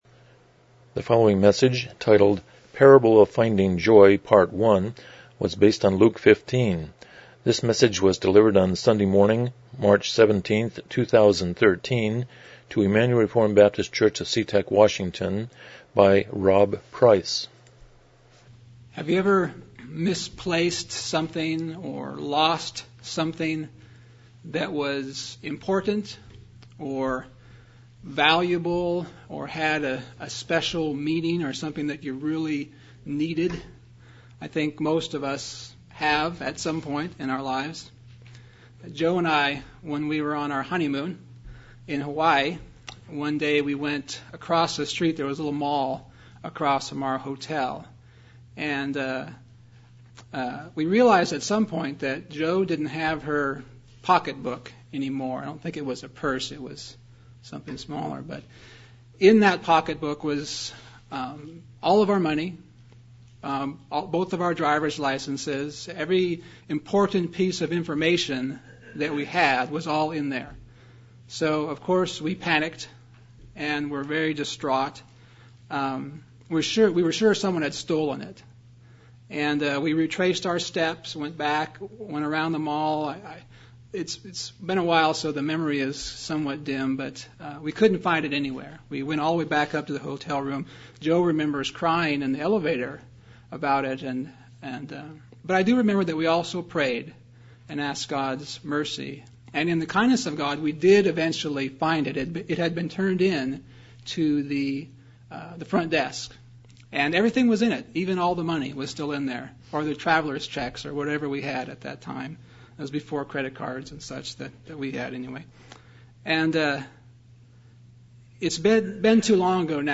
Luke 15:1-32 Service Type: Morning Worship « 2 What is a Prophet The Parable of “Finding Joy”